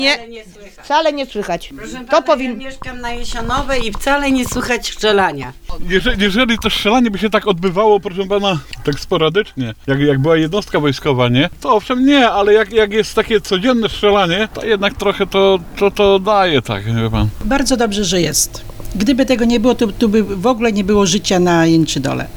sonda jeczydol.mp3